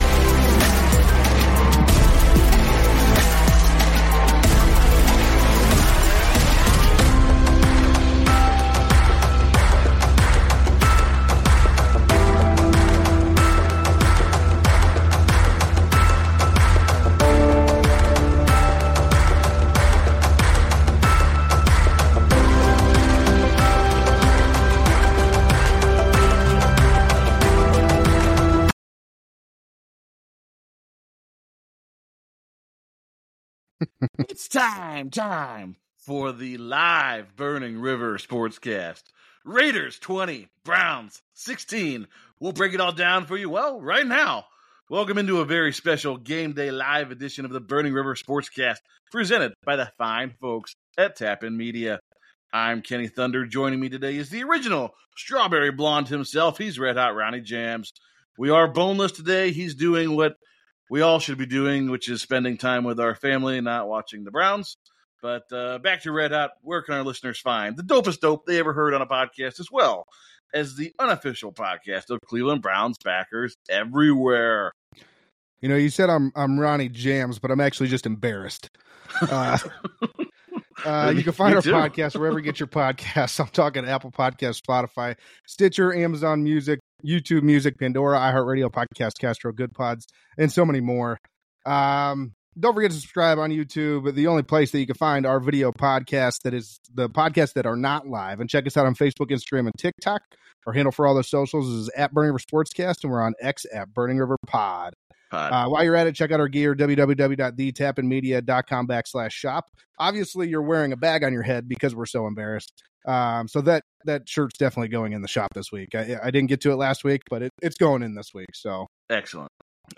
It's time! Time for Burning River Sportscast to go live again! Join as we discuss another disappointing Cleveland Browns loss. This time to the Las Vegas Raiders.